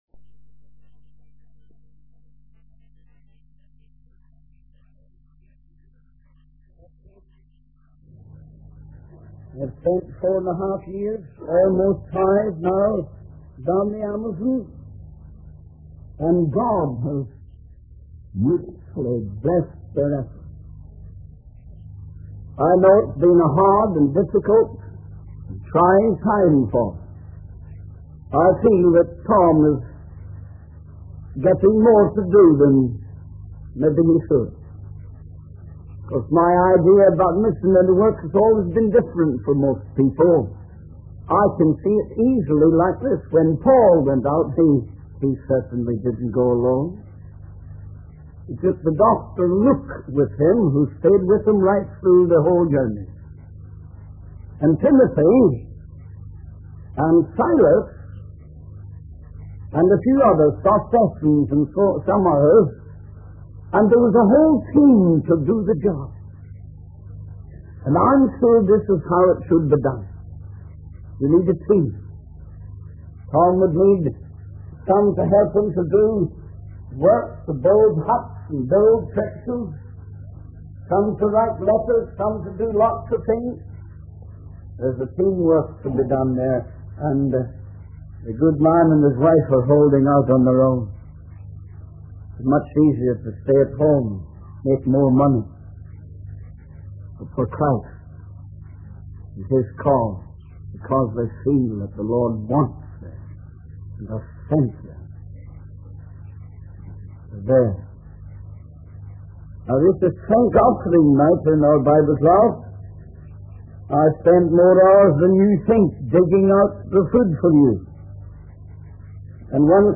In this sermon, the speaker begins by expressing gratitude for being at Brevin Baptist Church and shares what God has been doing and will continue to do. He reads a few verses from Mark's Gospel chapter 13, emphasizing the importance of being watchful for the return of the master of the house.